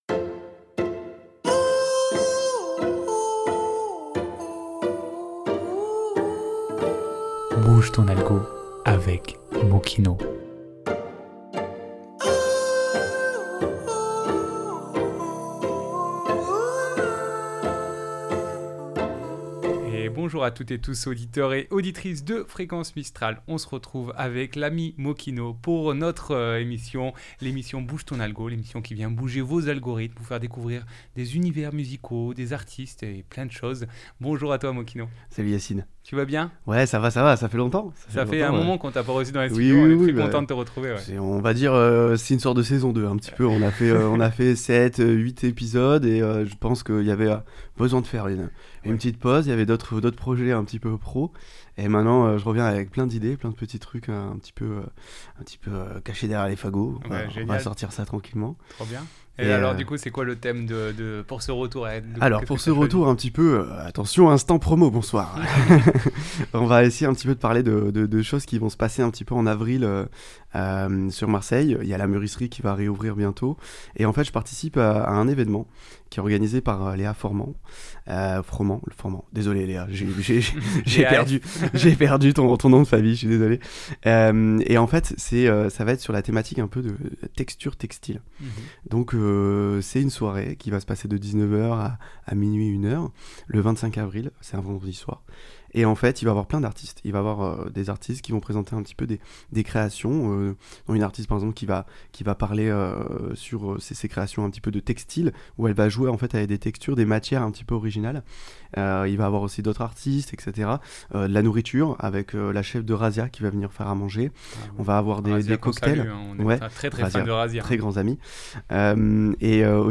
explorant la texture du son